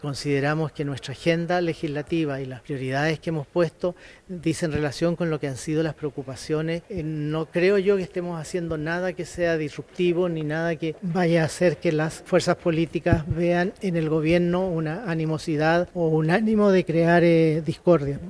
A lo mismo apuntó el ministro Segpres, José García Ruminot, quien -en un tono diferente- defendió el actuar de La Moneda y planteó que no han hecho nada que diste de normal.